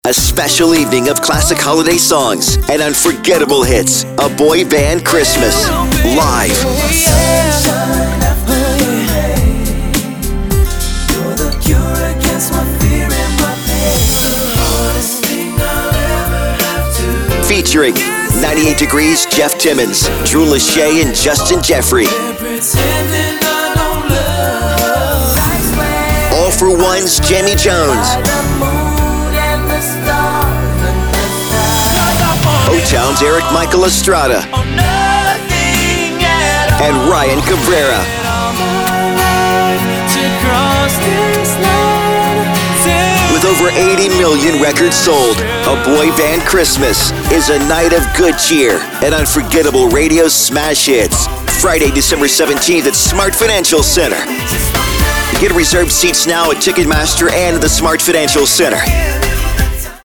Radio Spot
A-Boy-Band-Christmas-Sample-v1_60-Radio.mp3